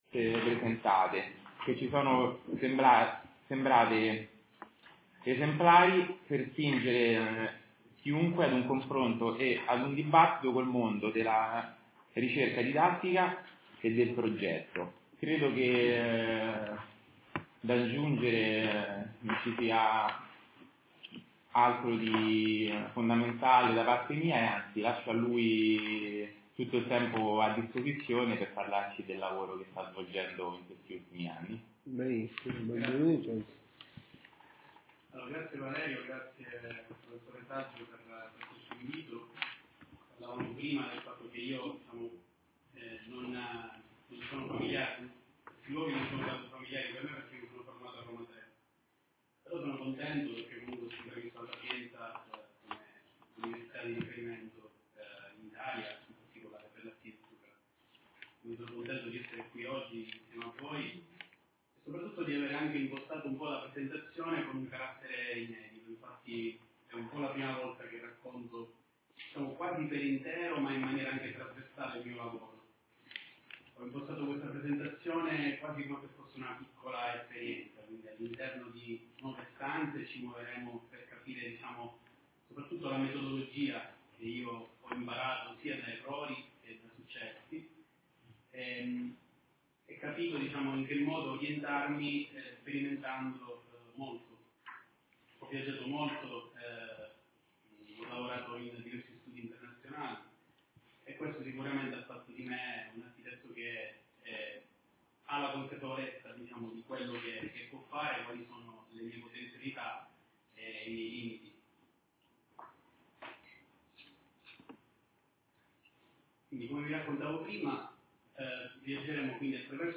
Tavola rotonda